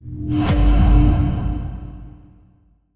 DreadChime.mp3